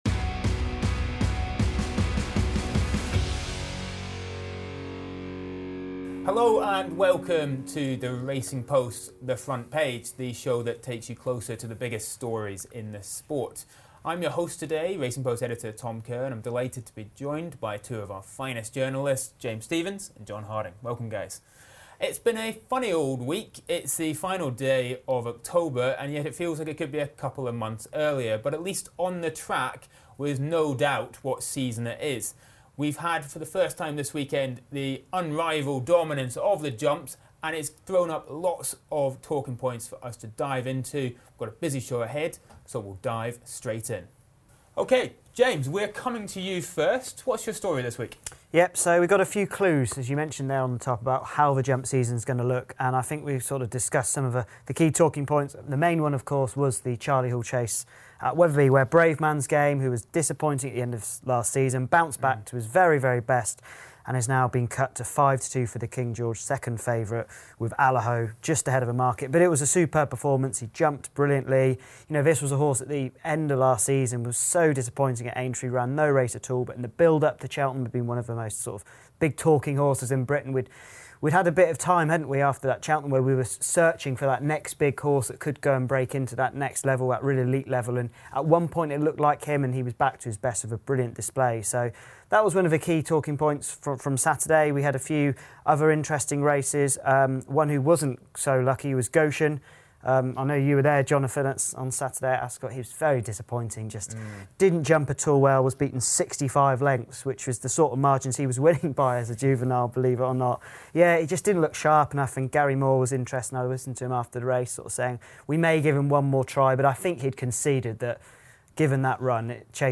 On this week's episode, the panel discuss Bravemansgame brilliant Charlie Hall Chase victory and his chances of winning the King George on Boxing Day.